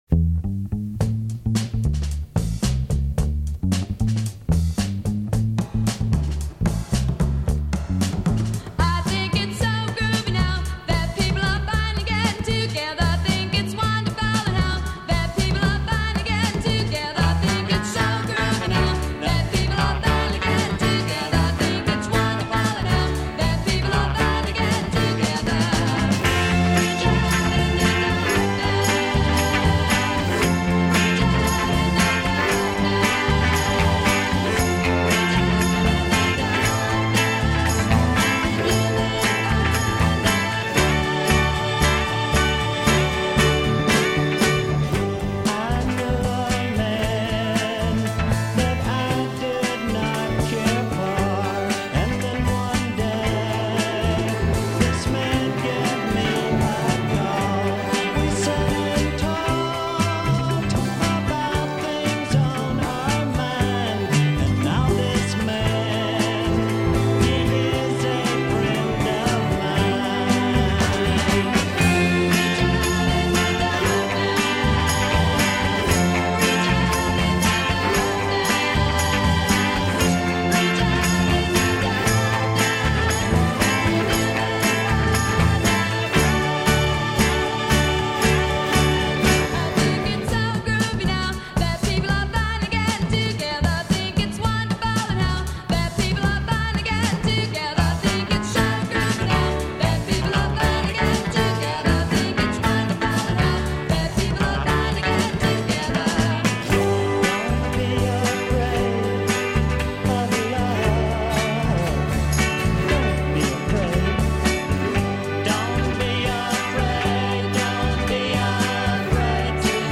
husband and wife duo
Dig the groovy tune and get hip to the message, man.